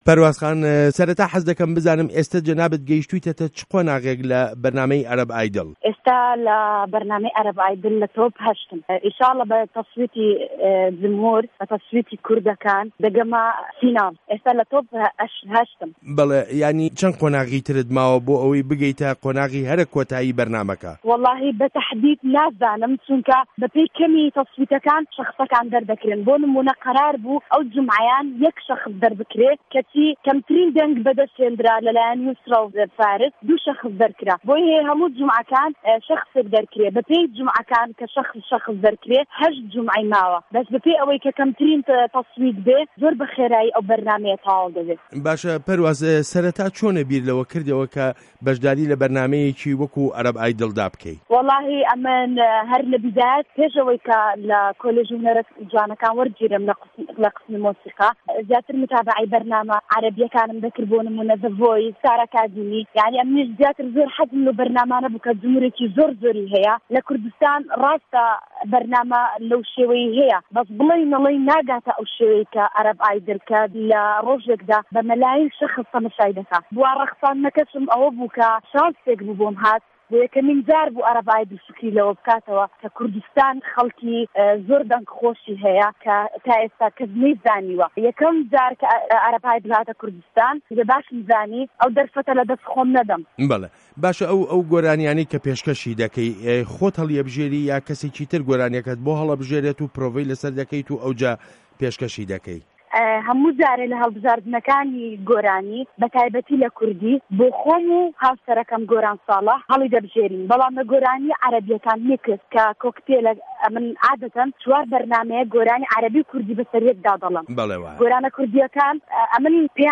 وتووێژ له‌گه‌ڵ په‌رواس حوسه‌ین